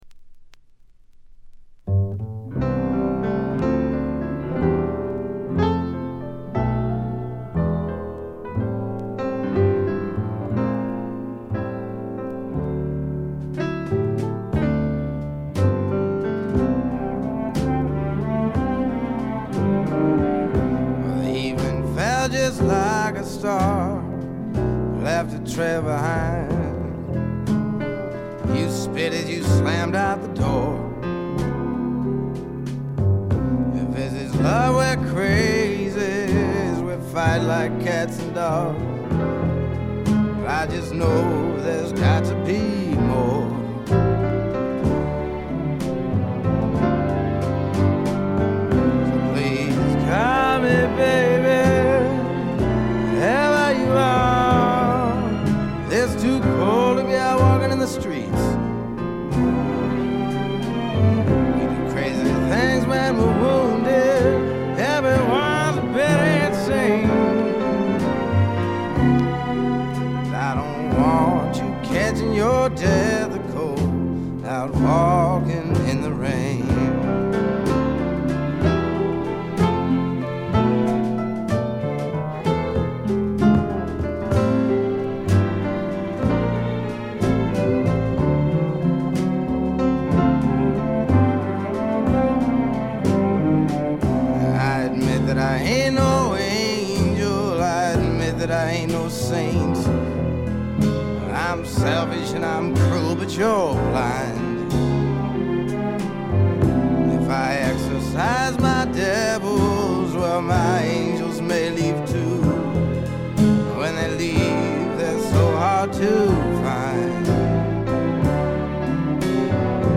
軽微なチリプチ程度。
メランコリックでぞっとするほど美しい、初期の名作中の名作です。
試聴曲は現品からの取り込み音源です。